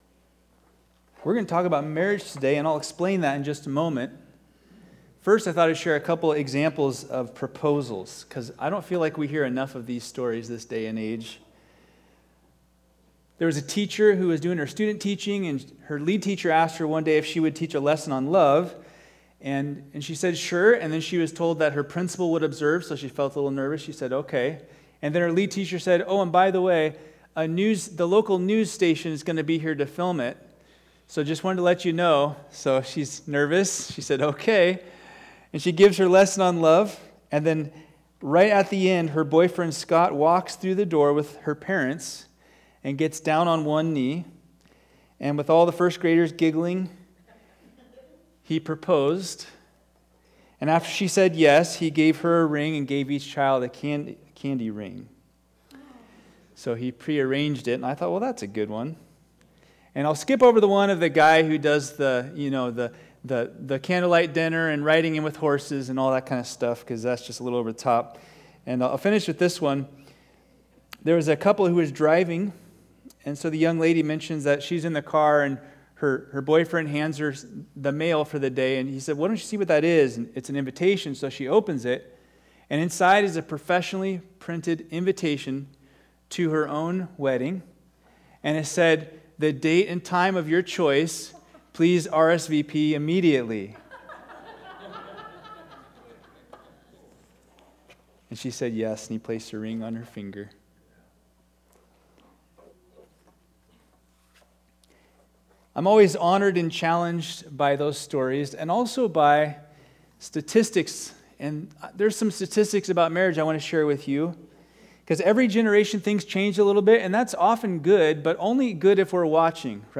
All Sermons Mutual Submission May 12